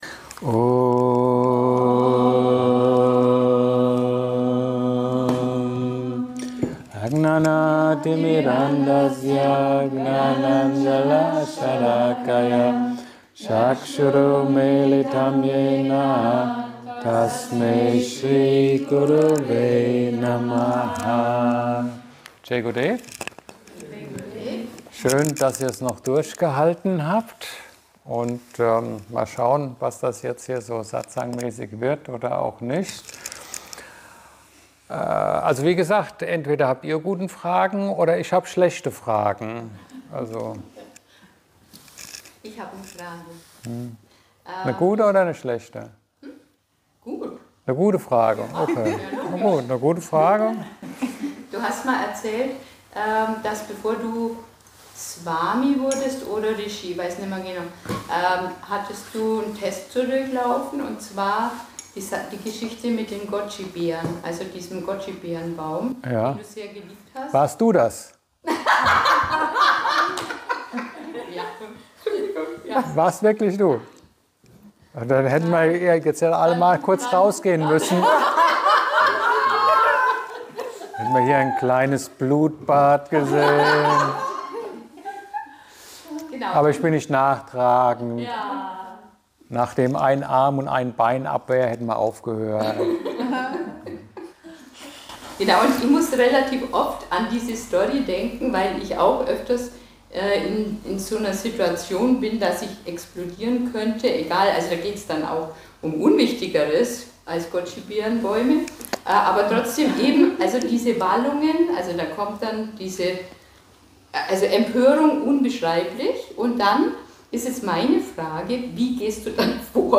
Satsang mit der Sangha im White Horse.